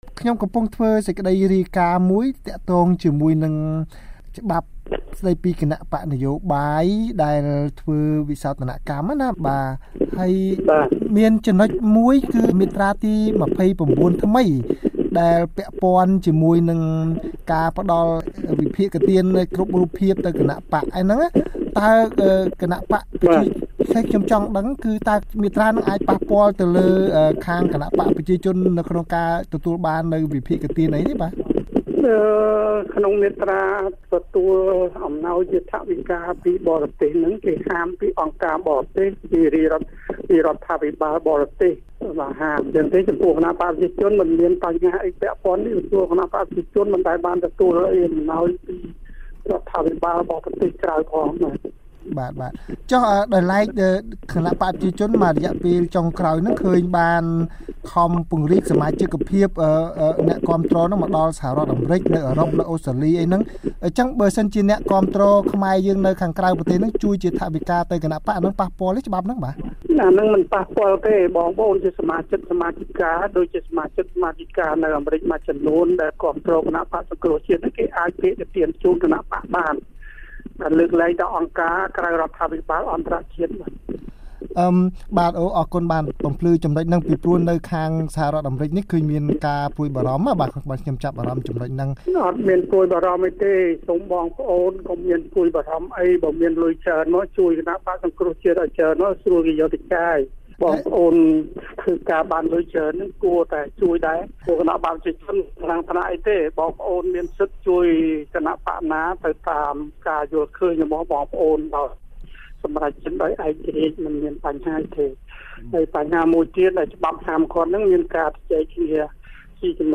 បទសម្ភាសន៍ VOA៖ បក្សប្រជាជនថាច្បាប់គណបក្សមិនរឹតត្បិតការផ្តល់ថវិកាពីខ្មែរ-អាមេរិកាំង